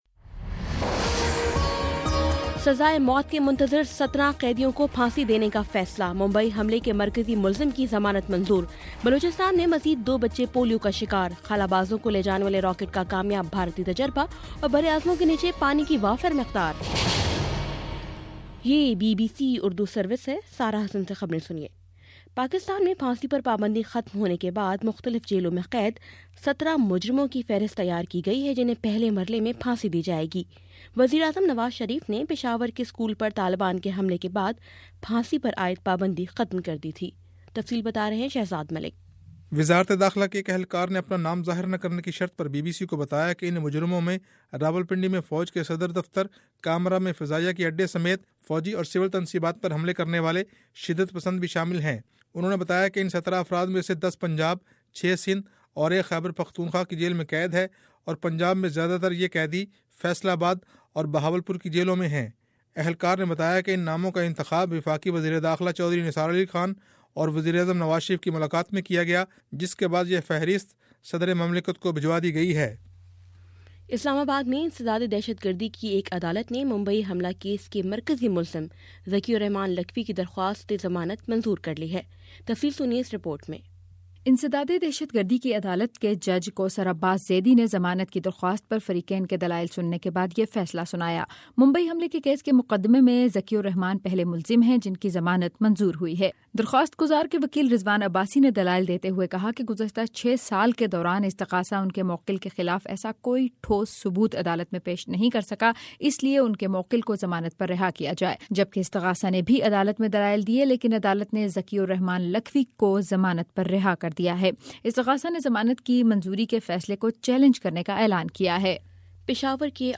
دسمبر 18 : شام چھ بجے کا نیوز بُلیٹن